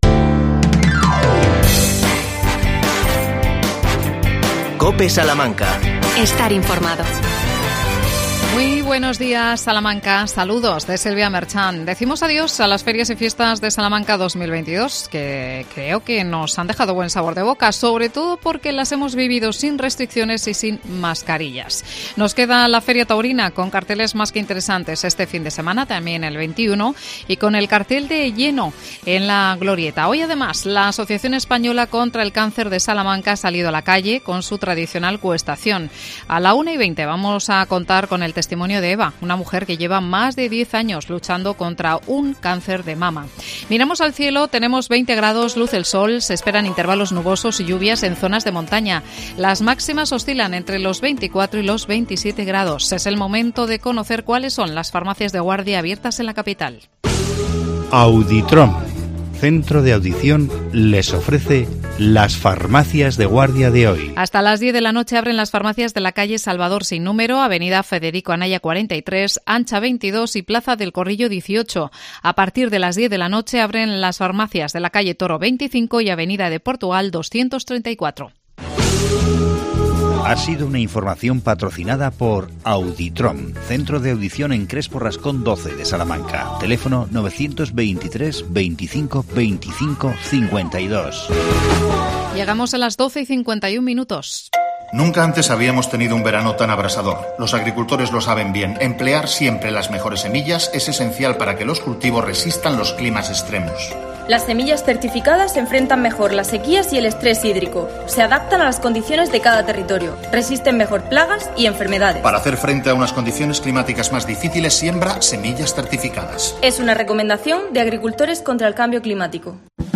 AUDIO: Mañana se presenta la Lonja de los Huertos Urbanos. Entrevistamos a la concejala de Medio Ambiente Miriam Rodríguez.